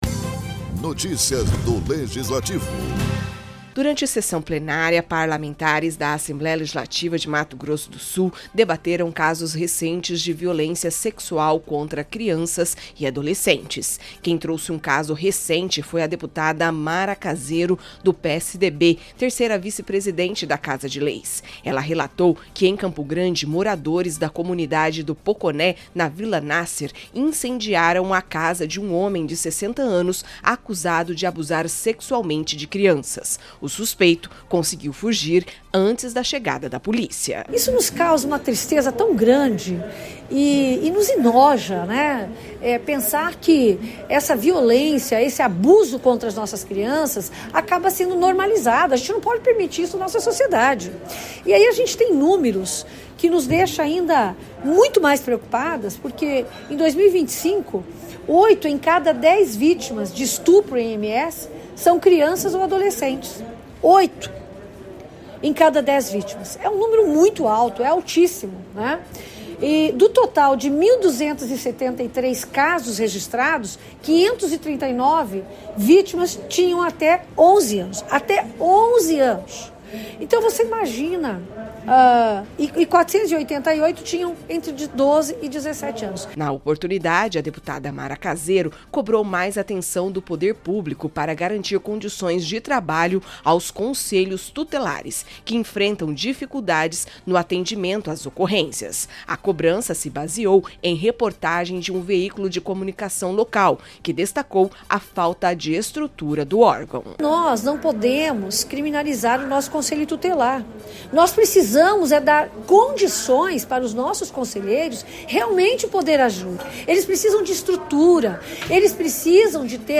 Durante sessão plenária, a Assembleia Legislativa de Mato Grosso do Sul discutiu casos recentes de violência sexual contra crianças. A deputada Mara Caseiro (PSDB) relatou episódio ocorrido em Campo Grande e apresentou dados alarmantes, além de cobrar mais estrutura para os Conselhos Tutelares no atendimento às vítimas.